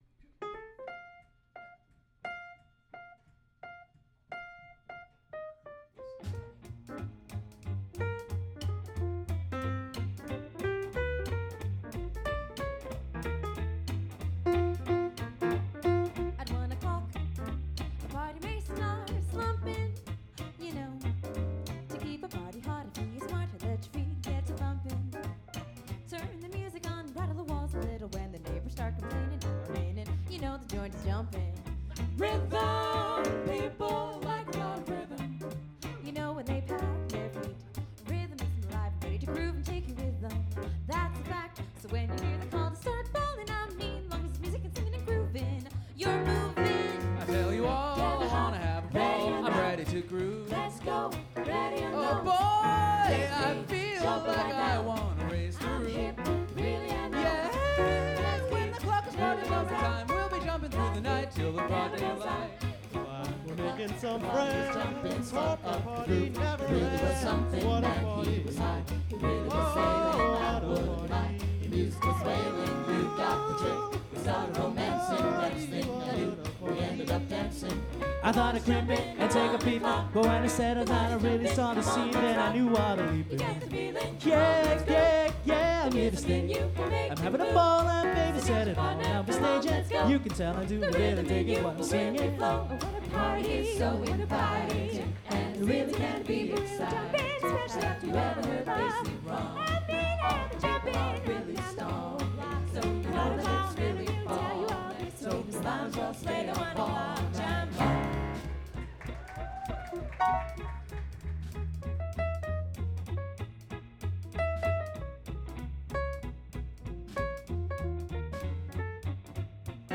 live 2008